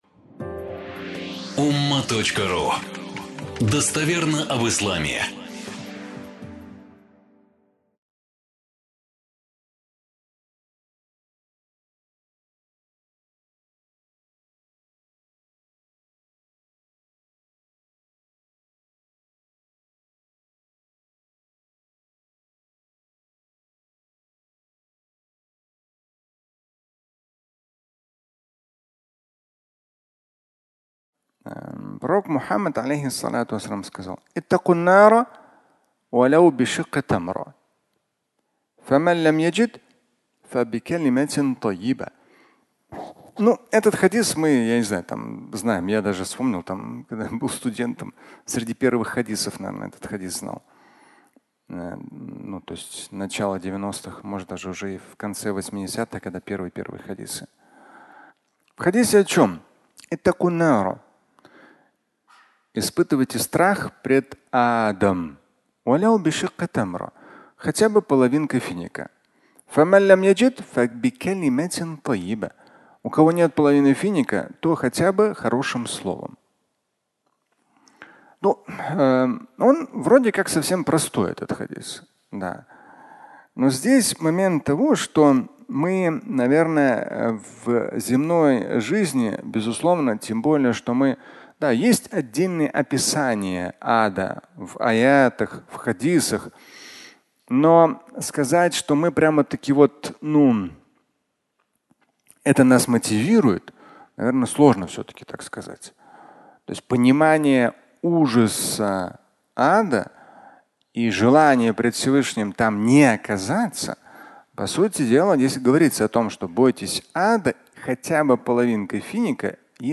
Мотивация (аудиолекция)